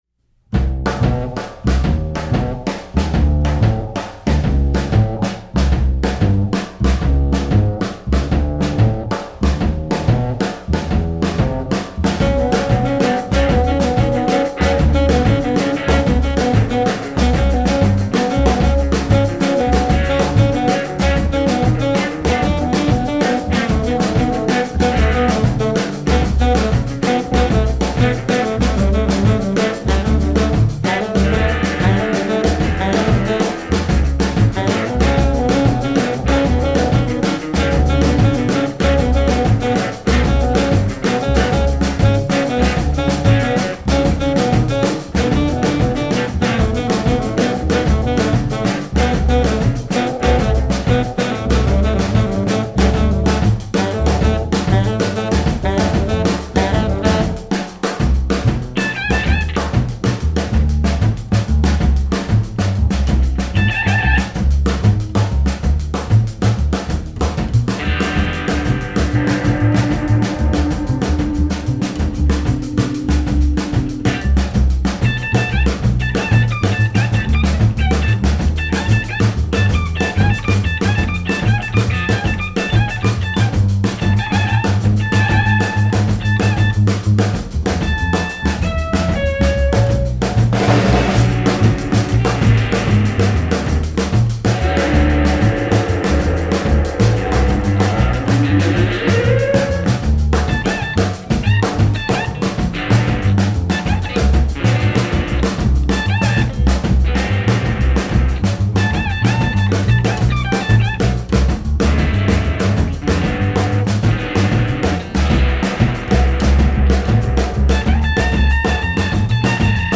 recorded at 2-od Studio, Brooklyn, New York